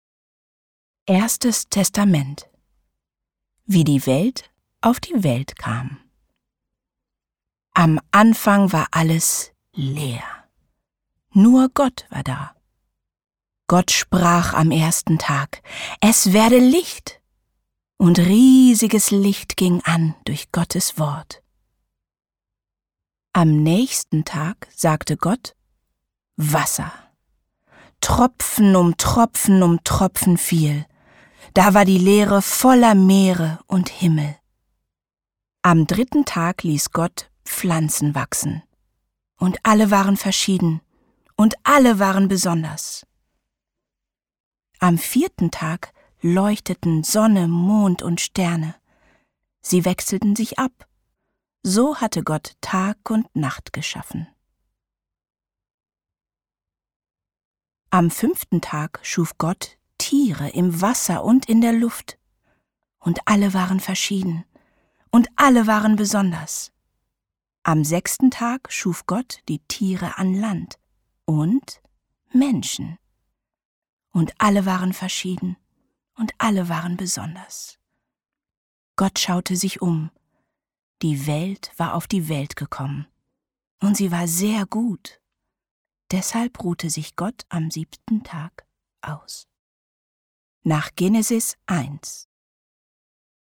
Alle-Kinder-Bibel Hörbuch